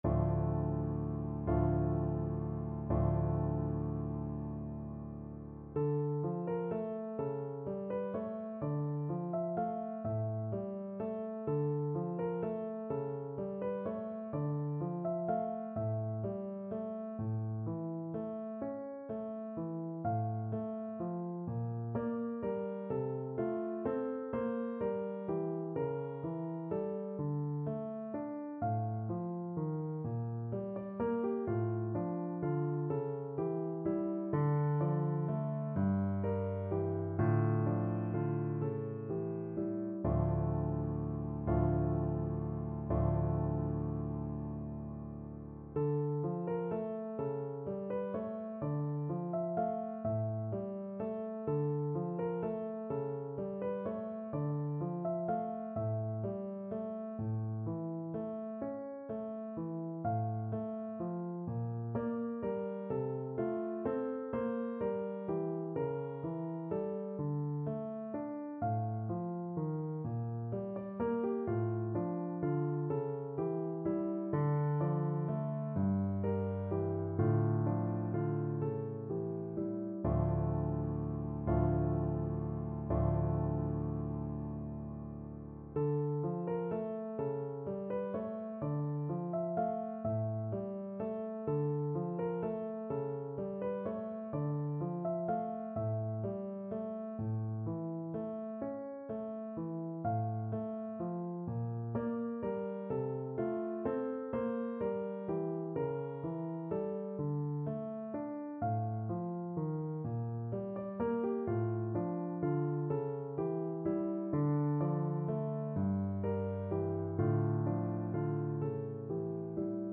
No parts available for this pieces as it is for solo piano.
6/8 (View more 6/8 Music)
Piano  (View more Intermediate Piano Music)
Classical (View more Classical Piano Music)